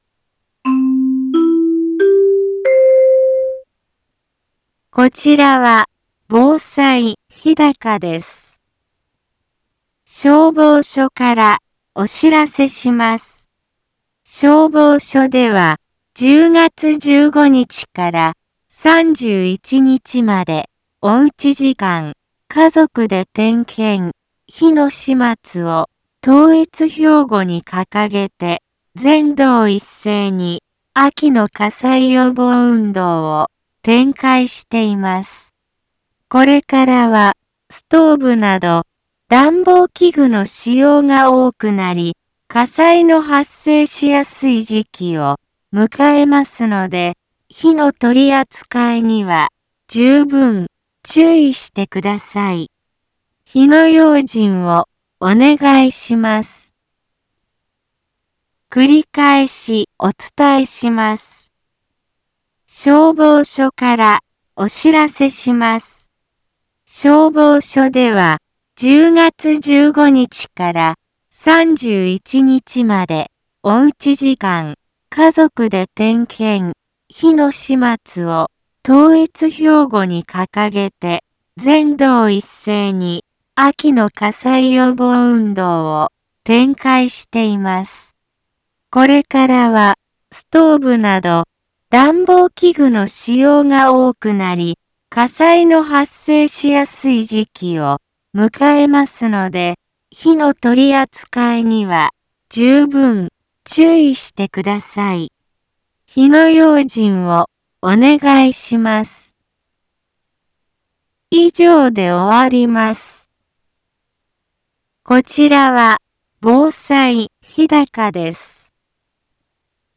一般放送情報
Back Home 一般放送情報 音声放送 再生 一般放送情報 登録日時：2021-10-15 10:04:22 タイトル：秋の火災予防運動 インフォメーション：こちらは防災日高です。